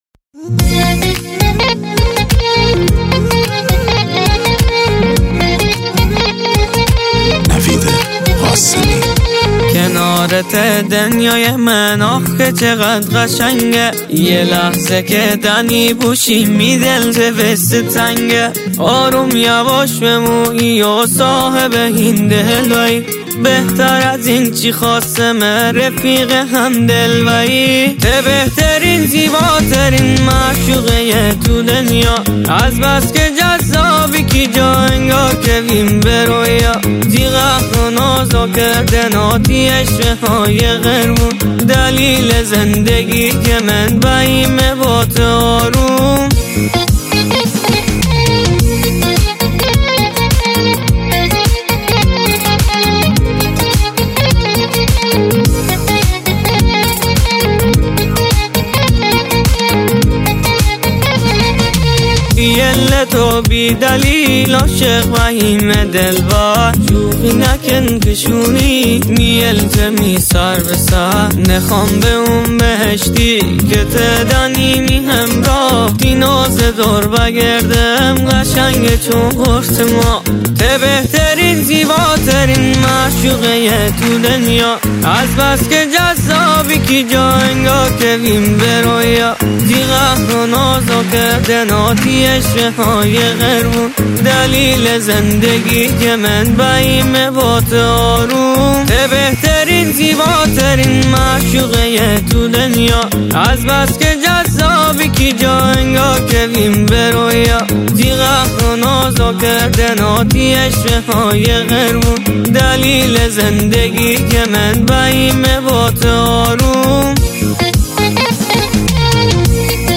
آهنگ مازندرانی